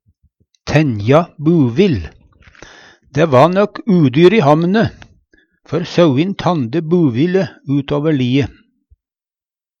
DIALEKTORD PÅ NORMERT NORSK tenja buvill tenje; springe fort, hardt utan mål og meining Eksempel på bruk Dæ va nøk udyr i hamne, før sauin tande buville utover lie.